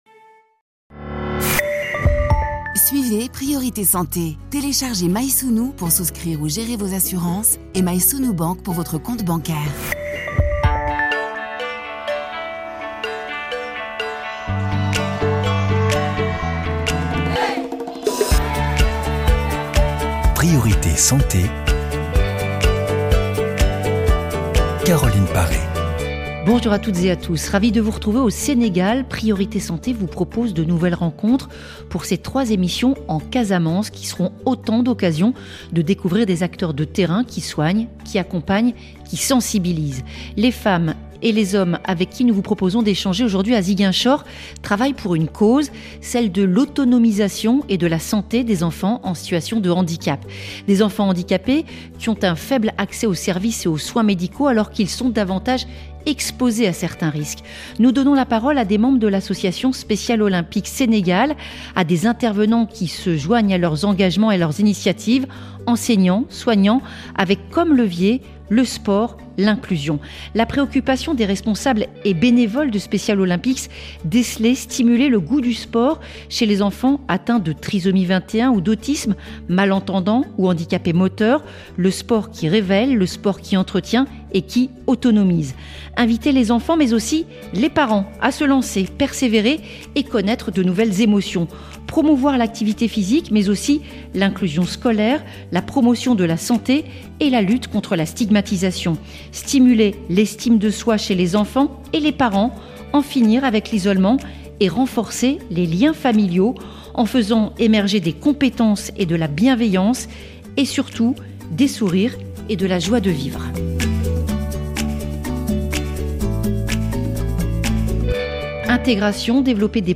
reçoit en direct des spécialistes pour faire le point sur l’actualité médicale dans le monde. Le but : faire de la prévention auprès du grand public, l’informer sur ses droits, sur les traitements et les moyens d’y accéder.